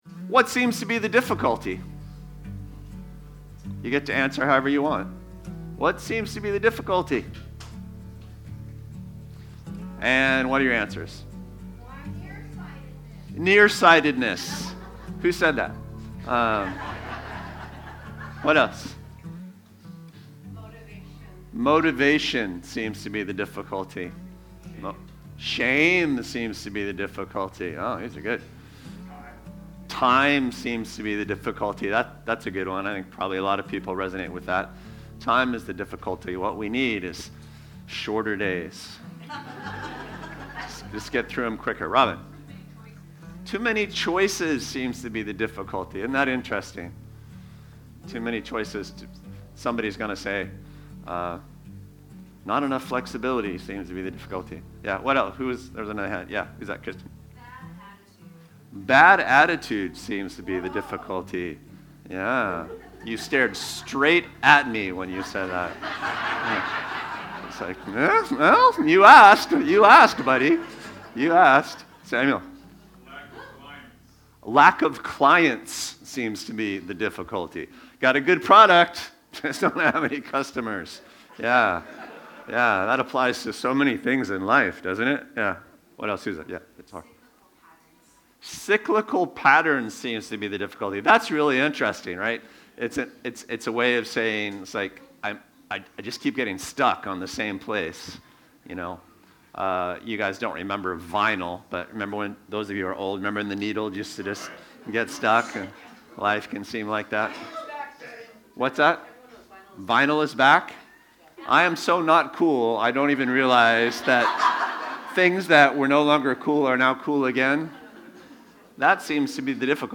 Podcasts of Bluewater Mission sermons, updated weekly.